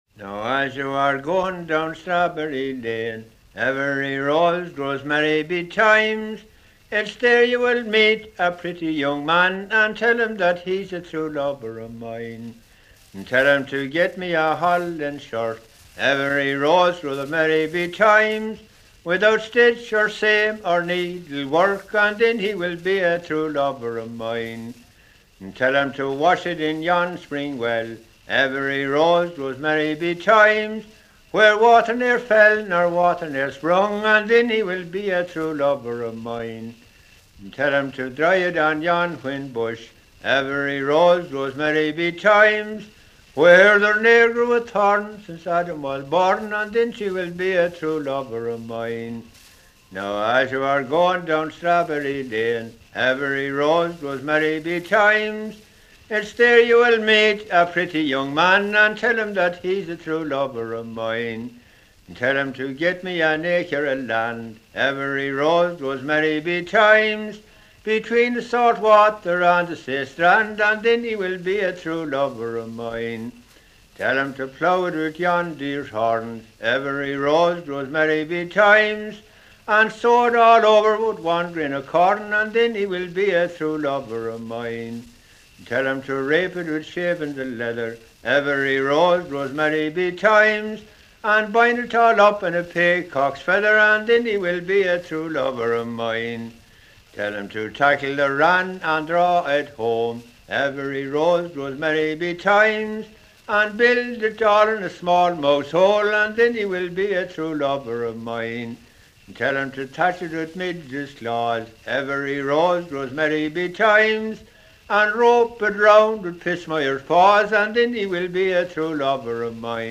BALLATE DA INGHILTERRA, SCOZIA E IRLANDA